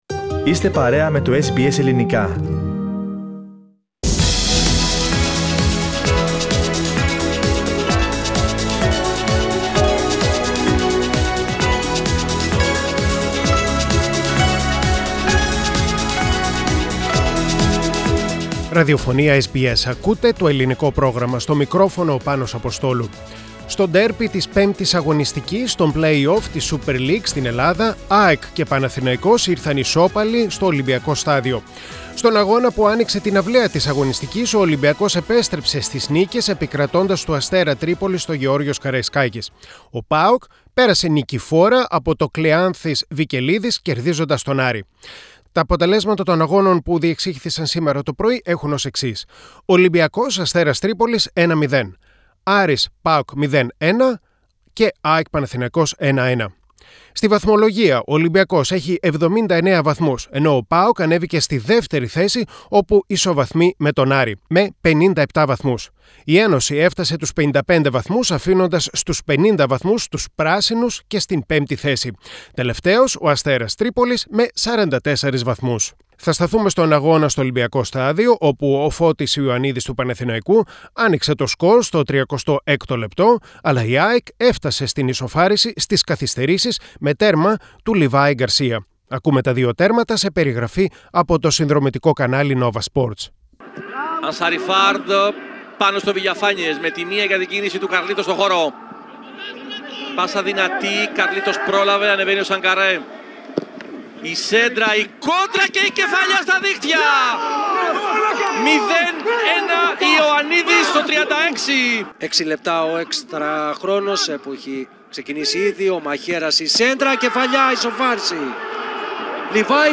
Τέννις και ποδόσφαιρο στο Αθλητικό Δελτίο της ημέρας (22.04.2021)